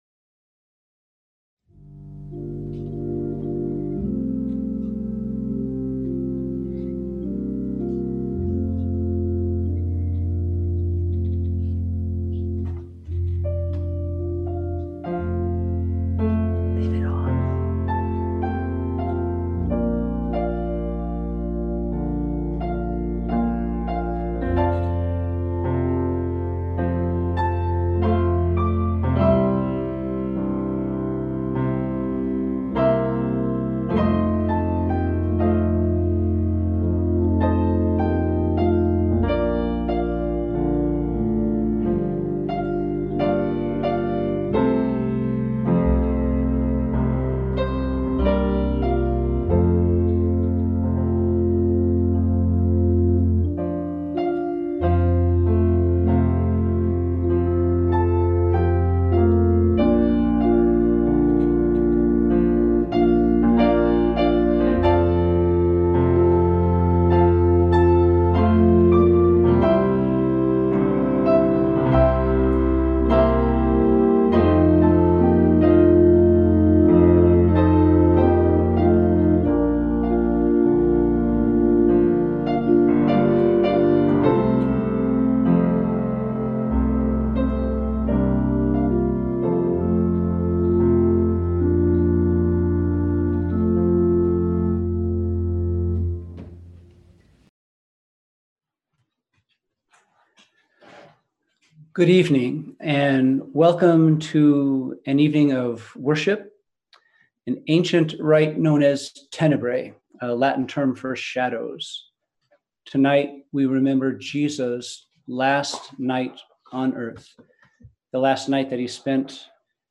We held a virtual Good Friday Tenebrae on Friday, April 10, 2020 at 7pm.